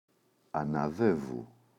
αναδεύου [ana’ðevu]